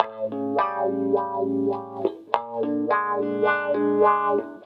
VOS GTR 1 -L.wav